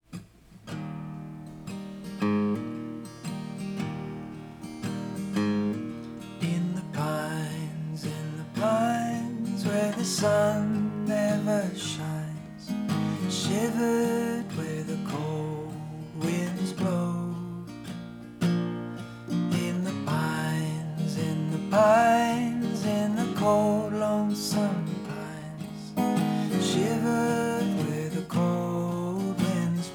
Жанр: Соундтрэки / Альтернатива